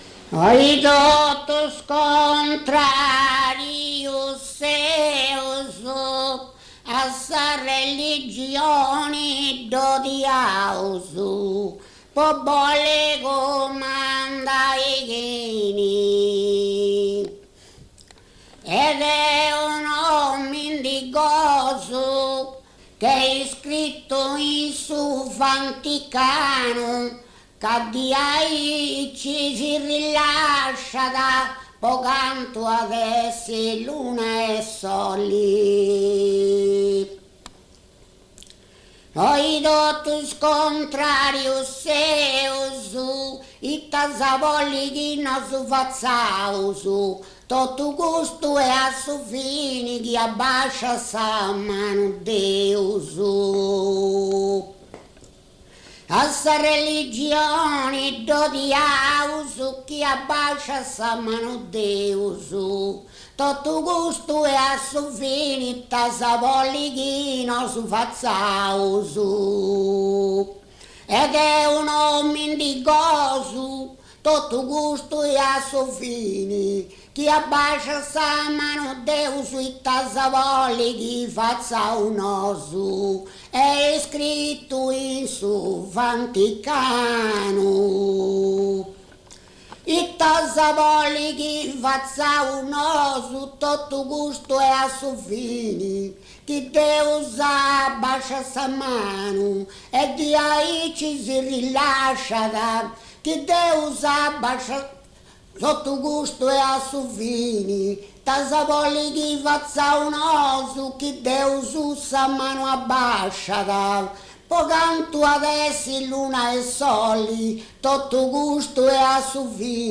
muttetu